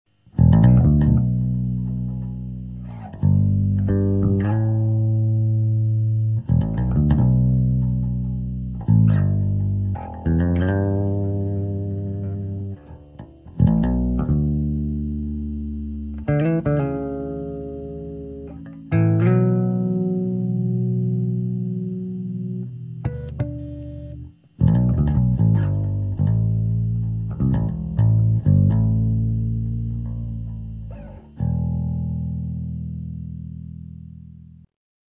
bass, mid, treble, volume , bright (le même type que pour la disto ).
voila trois samples du preamp enregistrés avec le fostex , en reprise micro sur un 15" .aucunes retouche , sauf une minuscule pointe de reverbe avec le fostex . c'est un exemple de reglage du preamp , de memoire basse 3/4 , mid 1/2 , treble 1/4 . bright en position milieu ( son naturel ) on peut donc l'aloudir ou le rendre plus brillant .
basse active shecter , preamp lampes , dagone 480 .
repris avec un micro akg d11 et un E906 , sur le fostex mr8 MKII .
sample_bass_clean.MP3